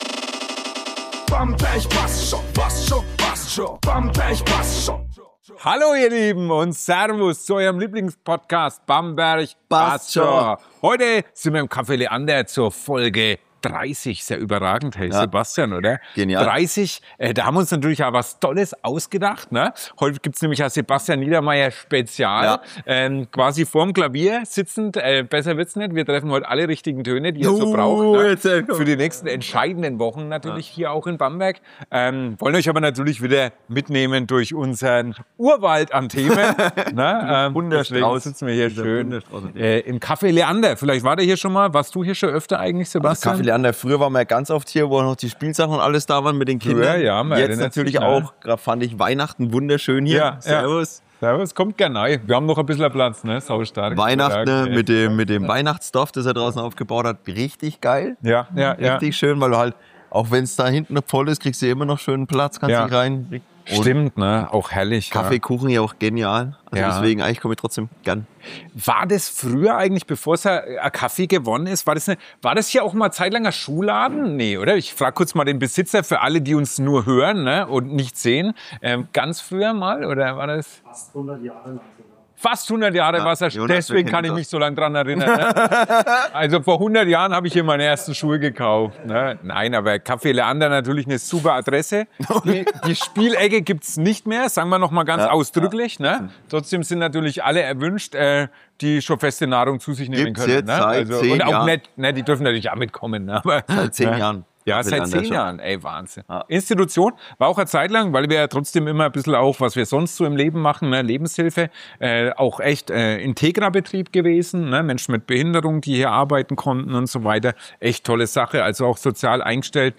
Für Folge 30 nehmen wir euch mit ins Café Leander – mit Kaffee, Klavier und ganz viel Bamberg.
Locker, ehrlich und mitten aus dem Bamberger Alltag.